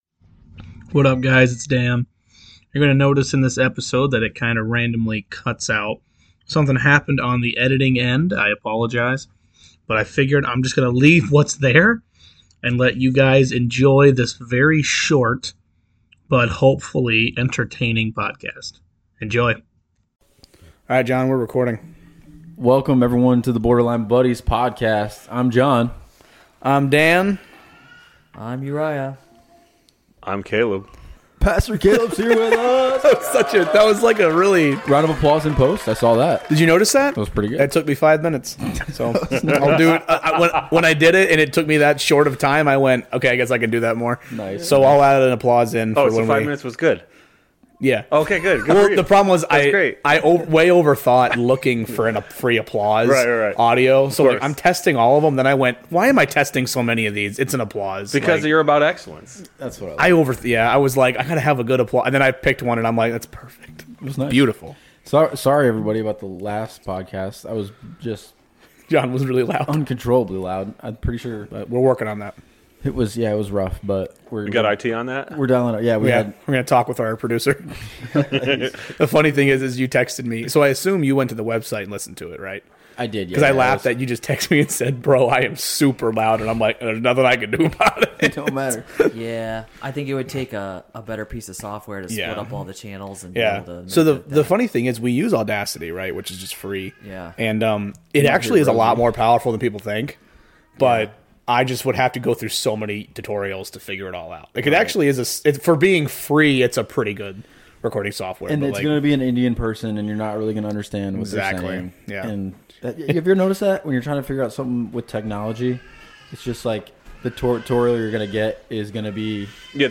Be a guest on this podcast Language: en Genres: Comedy , Improv , Stand-Up Contact email: Get it Feed URL: Get it iTunes ID: Get it Get all podcast data Listen Now...
We apologize the to the fans for this horrible editing error, we will do our best to never let this happen again.